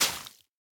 Minecraft Version Minecraft Version snapshot Latest Release | Latest Snapshot snapshot / assets / minecraft / sounds / block / sponge / wet_sponge / break1.ogg Compare With Compare With Latest Release | Latest Snapshot